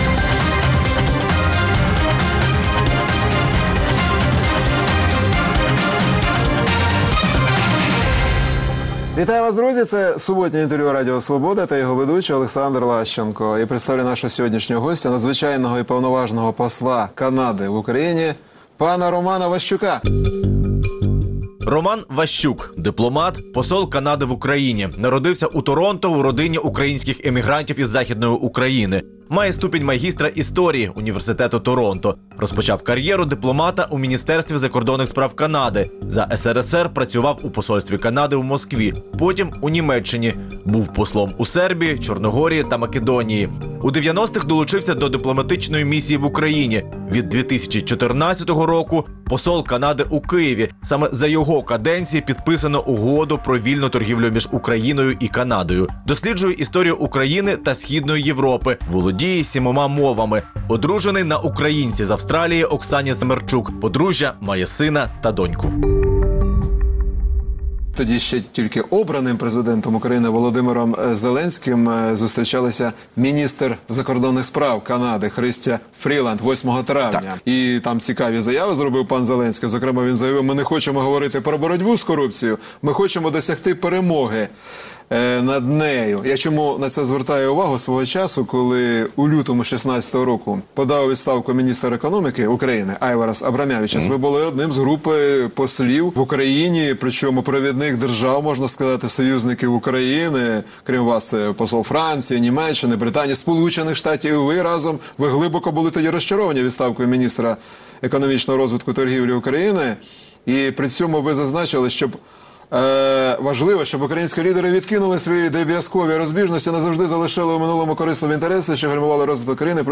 Суботнє інтерв’ю | Роман Ващук, посол Канади в Україні
Суботнє інтвер’ю - розмова про актуальні проблеми тижня. Гість відповідає, в першу чергу, на запитання друзів Радіо Свобода у Фейсбуці